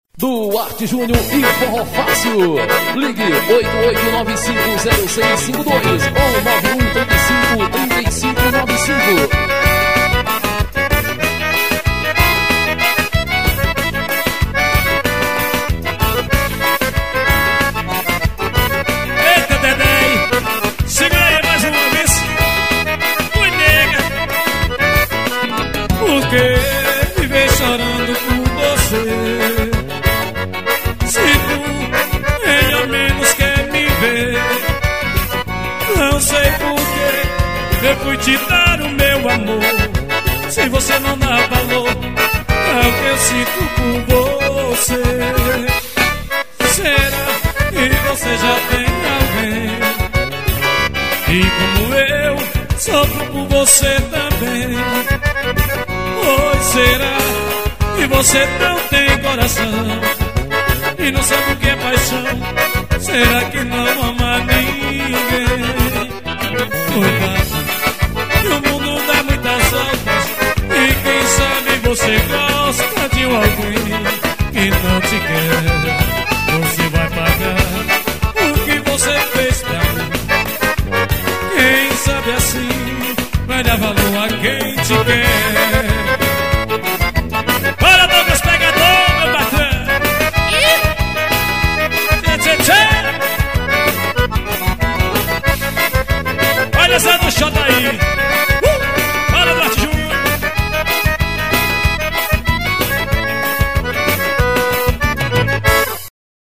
gravação de cd ao vivo.